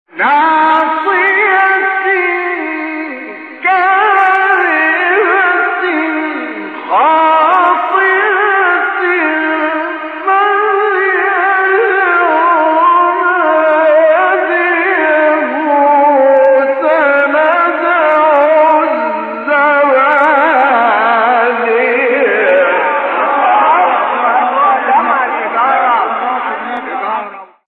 آیه 16-18 سوره علق استاد مصطفی اسماعیل | نغمات قرآن | دانلود تلاوت قرآن